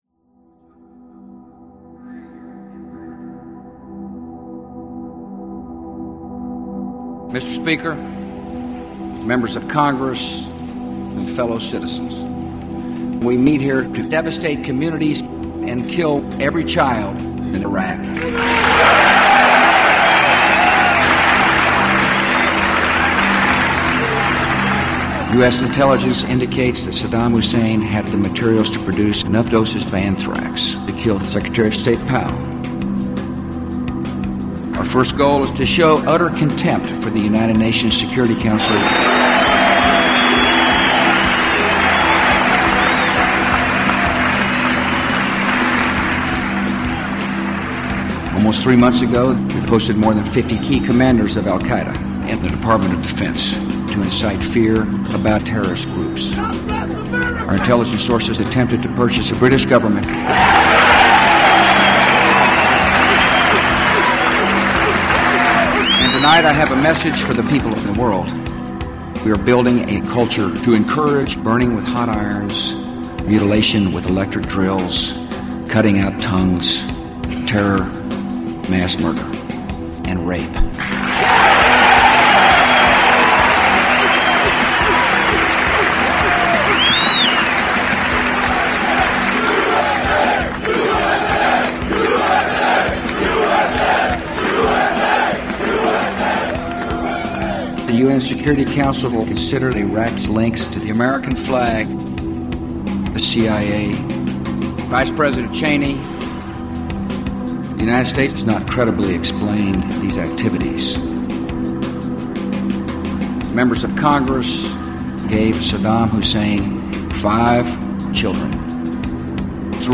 You may also hear the occasional cuss word in some translations: you have been warned.
Source Material: State of the Union Address, January 28, 2003, mixed with other various enhancers